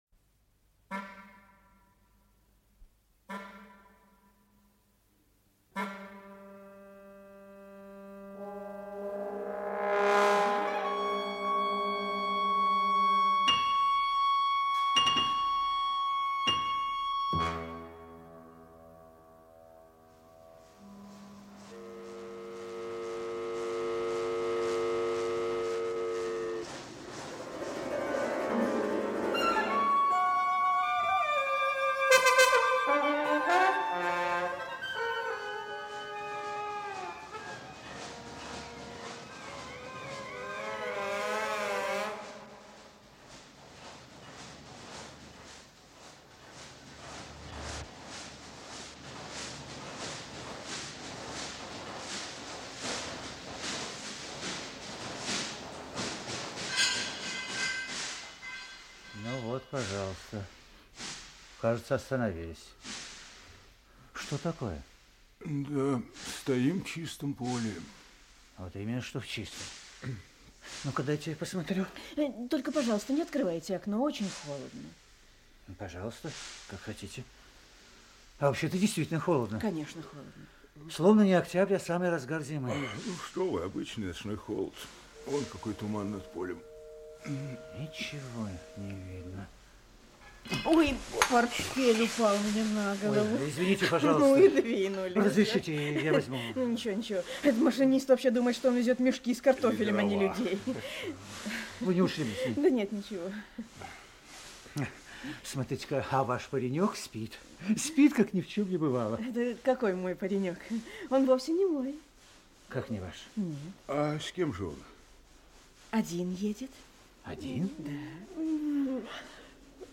Аудиокнига Цвет травы на заре | Библиотека аудиокниг
Aудиокнига Цвет травы на заре Автор Кристина Богляр Читает аудиокнигу Актерский коллектив.